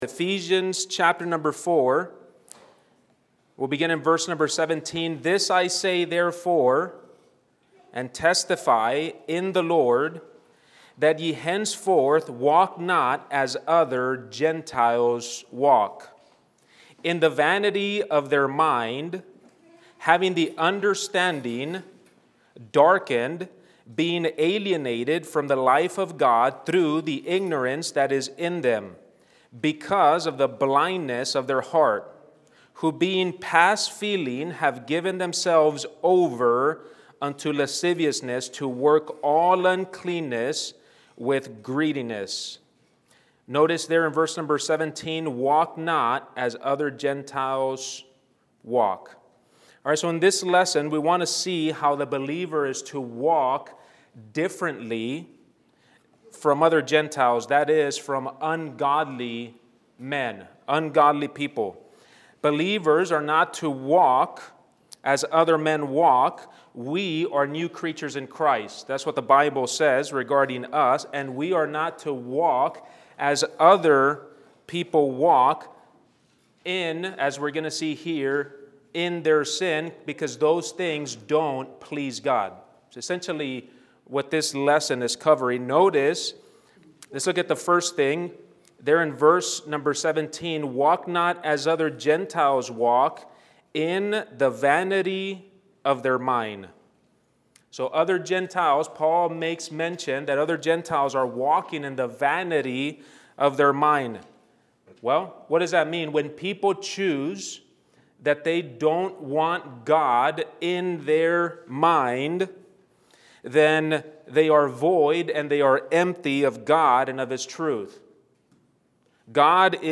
Introduction: In this lesson we are going to see that the Believer is to walk differently from the gentiles, that is, as ungodly men (Ephesians 2:11-12/I Cor 10:32).